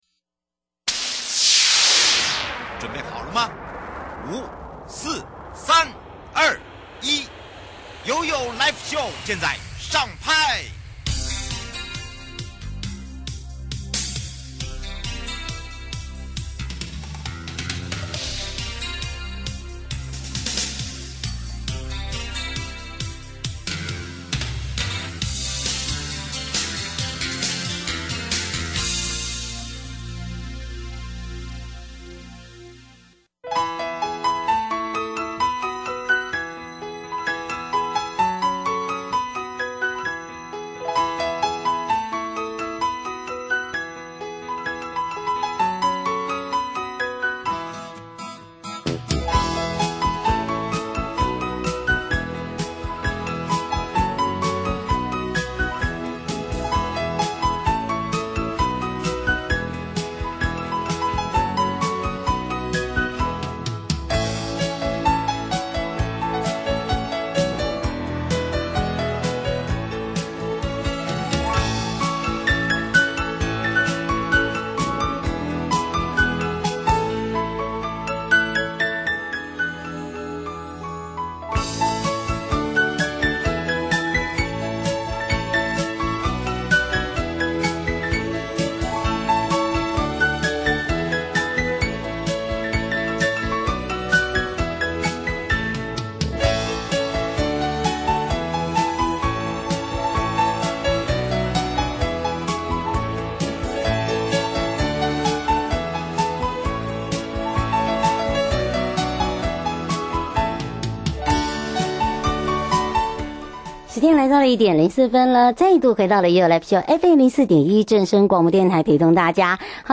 受訪者： 13:00-13:30 1.
13:30-14:00(同步直播) 1.